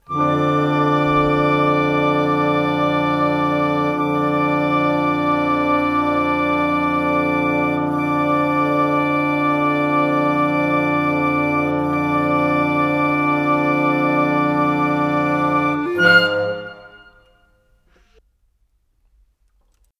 Cinematic 27 Woodwinds 01.wav